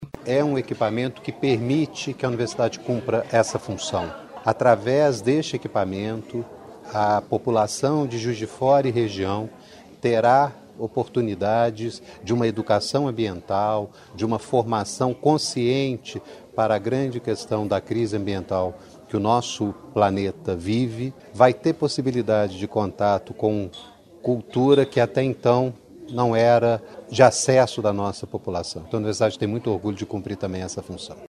Em evento fechado de inauguração, o reitor Marcus David falou sobre a função social do Jardim Botânico.
reitor da UFJF Marcus David